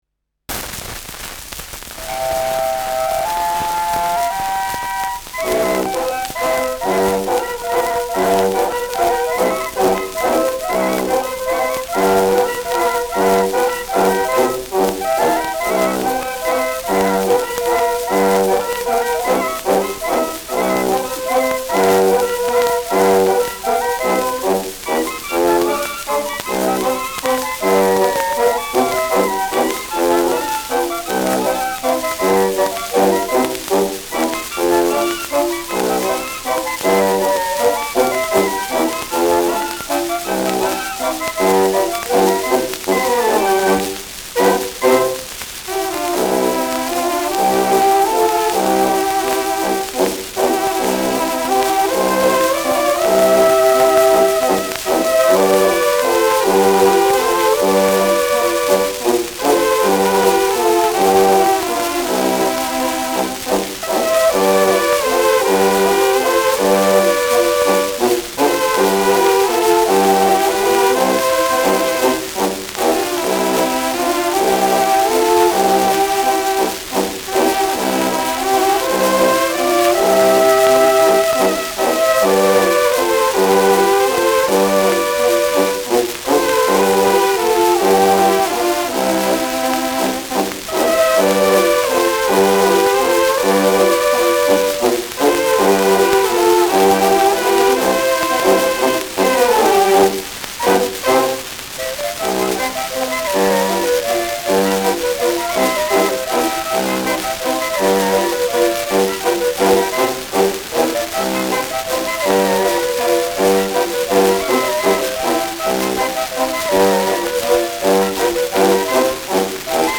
Schellackplatte
präsentes Rauschen : präsentes Knistern : stark abgespielt : leiert
Weana Ländler-Kapelle (Interpretation)
[Wien] (Aufnahmeort)